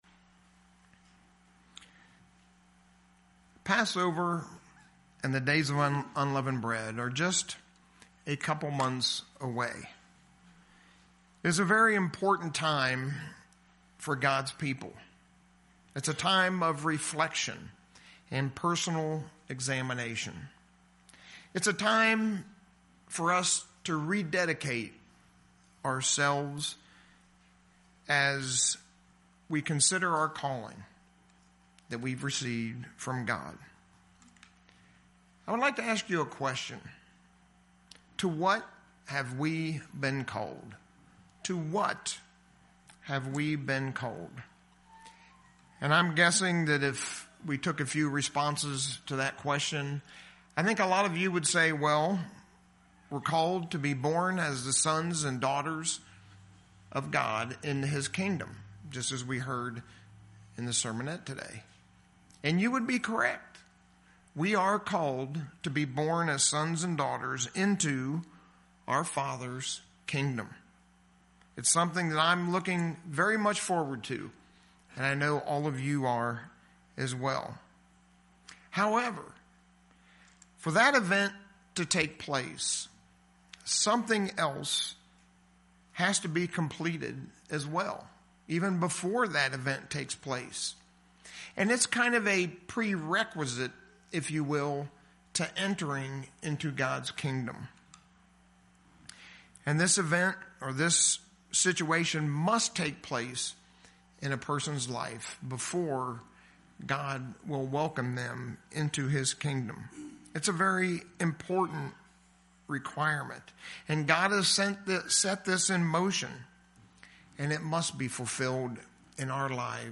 That prerequisite is that all must come to repentance in their life. In the sermon today, we'll see the Pathway to Repentance, that's so vital in our life, and vital to our entrance into God's Kingdom.